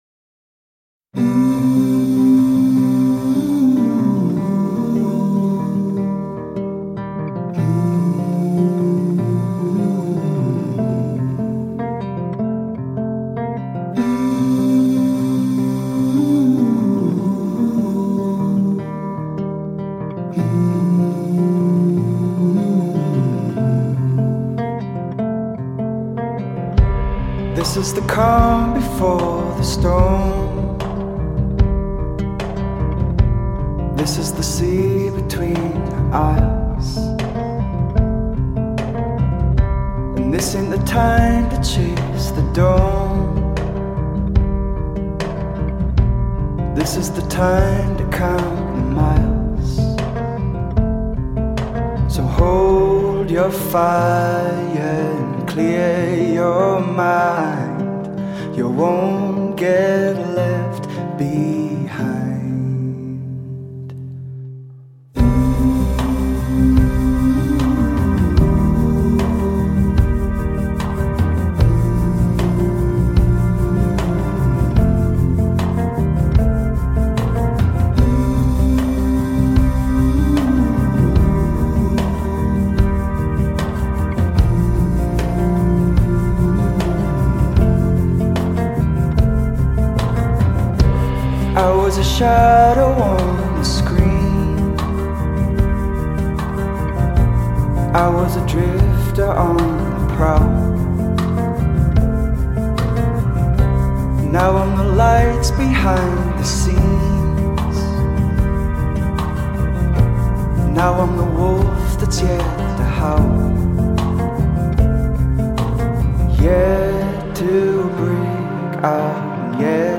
singer-songwriter
haunting soundscapes